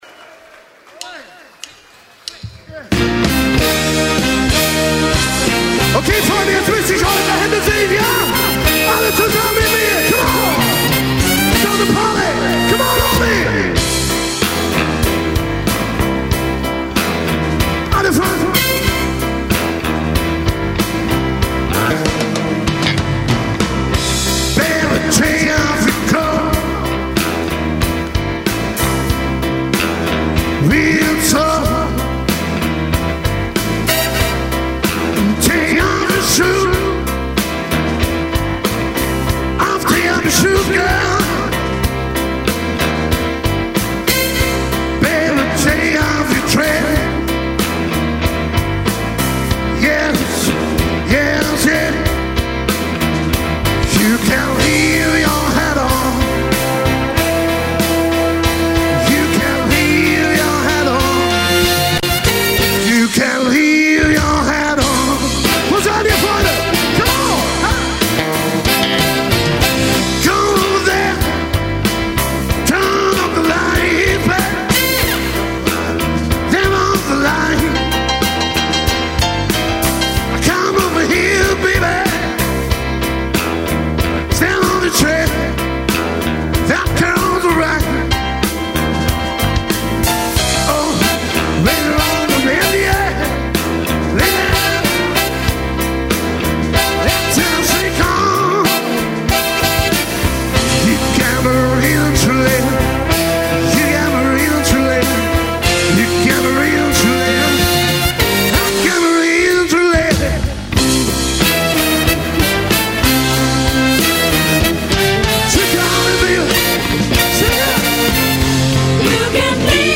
• Coverband
• Sänger/in